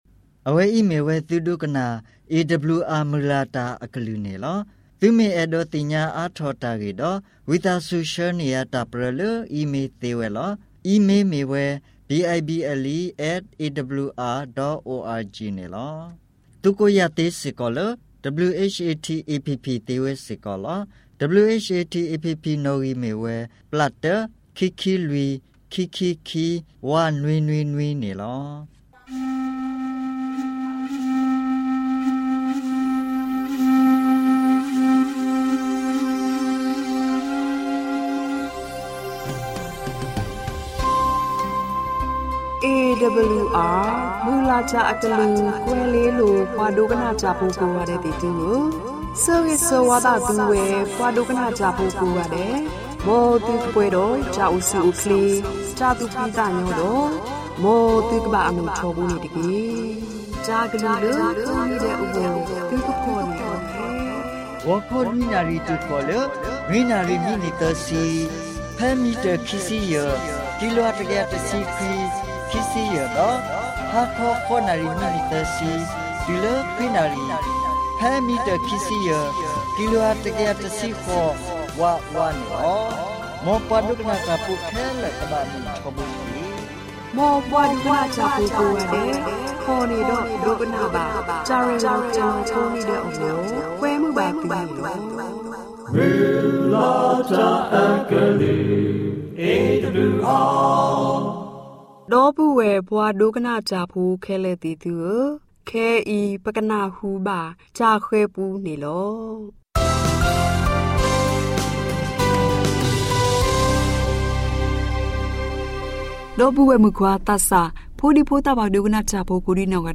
Karen radio program by Adventist World Radio